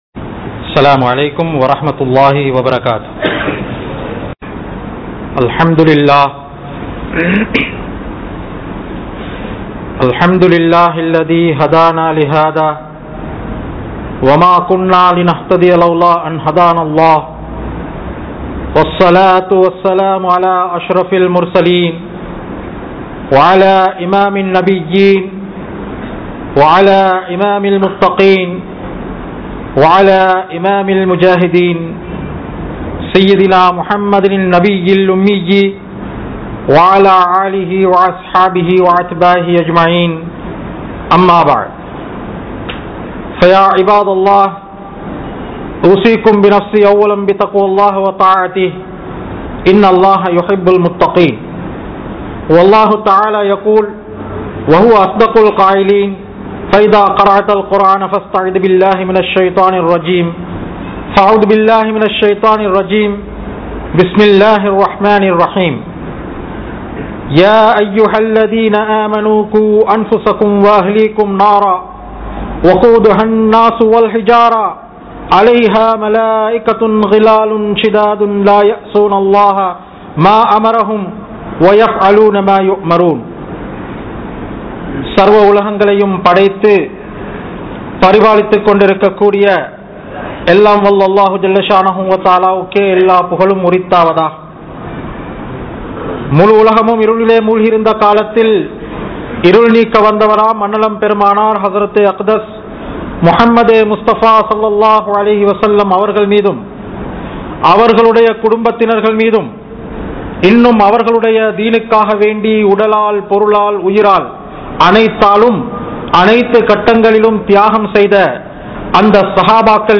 Social Media | Audio Bayans | All Ceylon Muslim Youth Community | Addalaichenai
Thaqwa Jumua Masjith